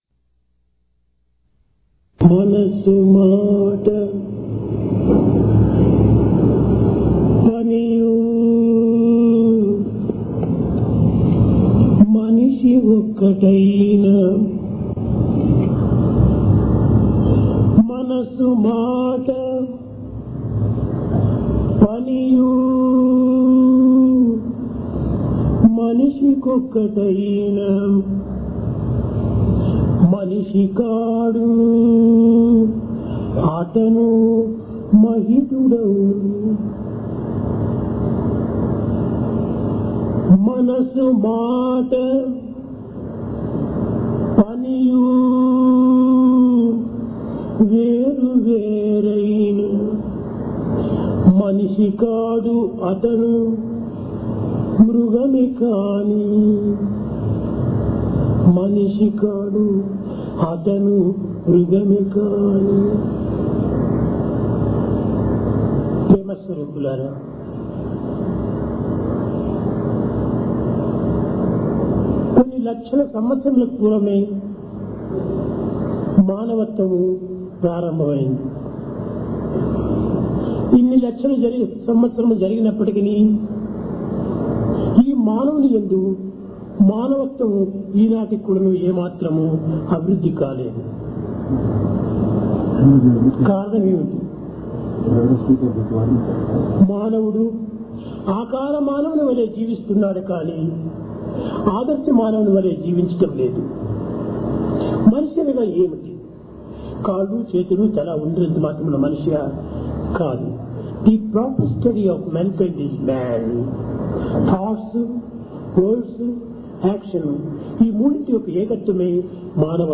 Divine Discourse | Sri Sathya Sai Speaks